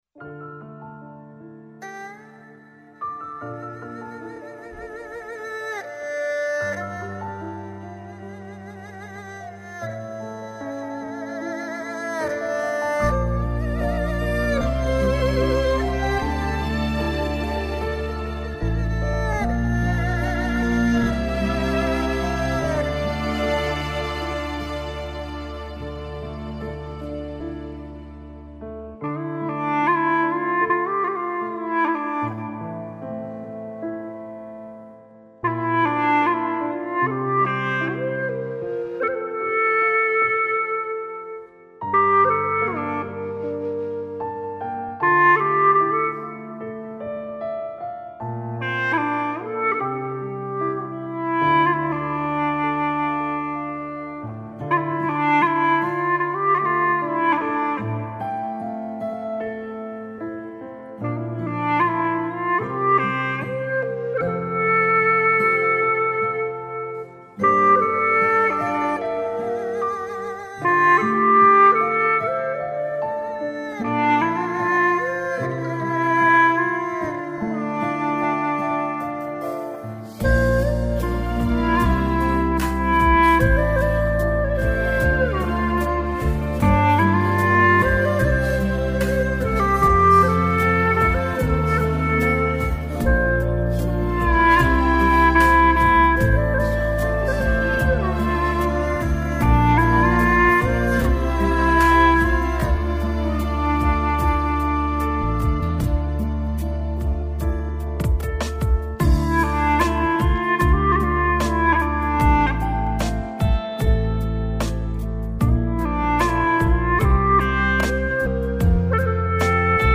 调式 : F 曲类 : 古风